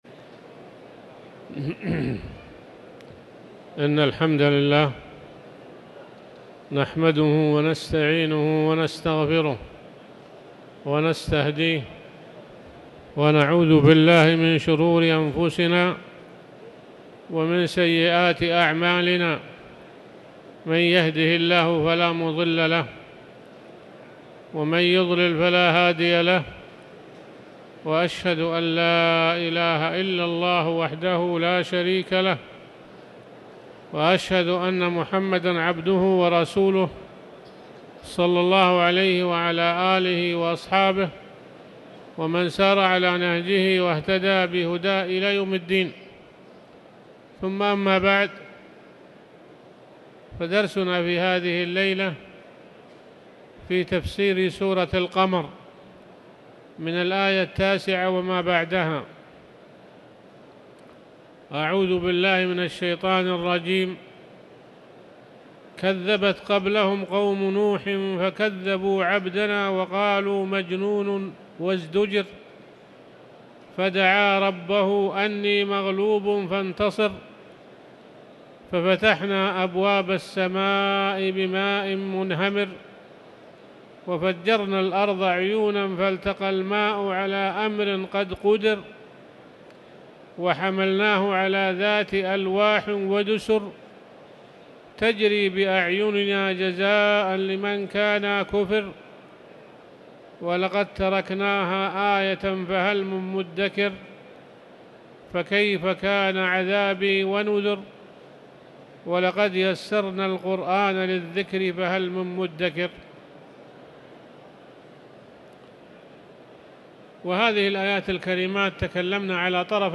تاريخ النشر ٢٠ جمادى الآخرة ١٤٤٠ هـ المكان: المسجد الحرام الشيخ